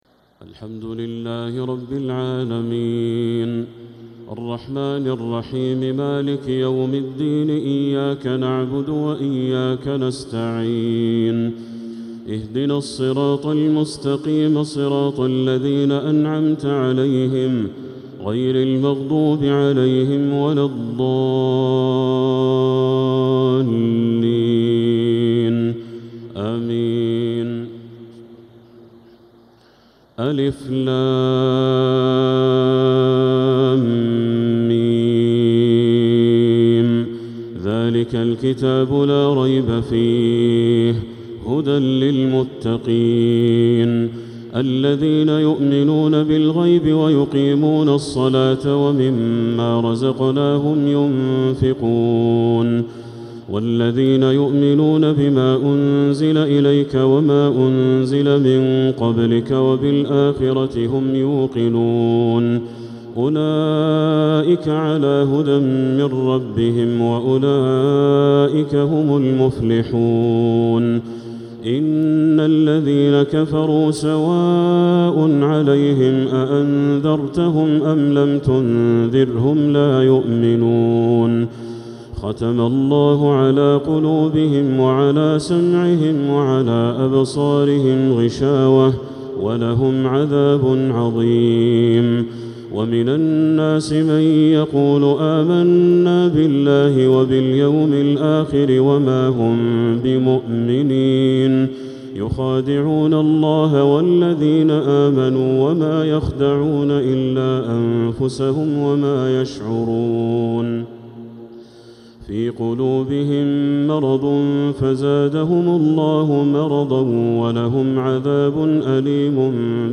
تراويح ليلة 1 رمضان 1447هـ من سورة البقرة (1-59) | Taraweeh 1st night Ramadan 1447H > تراويح الحرم المكي عام 1447 🕋 > التراويح - تلاوات الحرمين